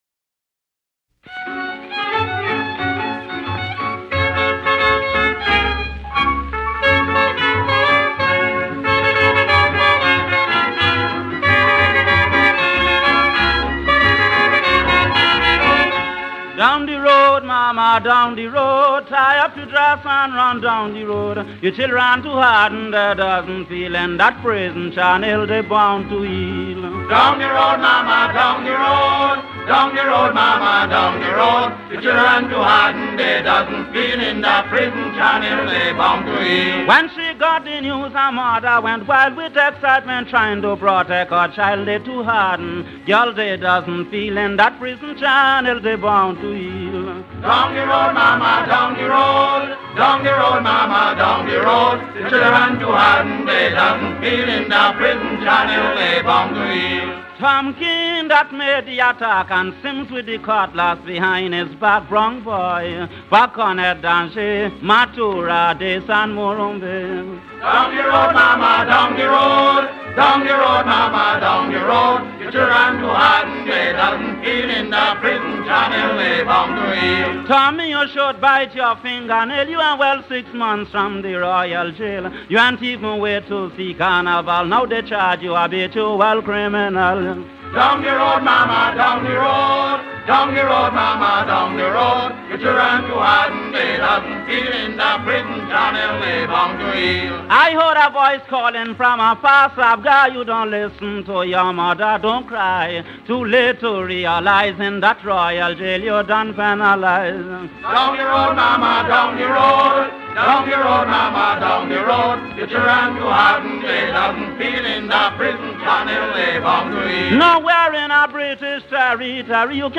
an audio component being a Calypso song from that time period addressing the violence of the riots and a fictional museum card.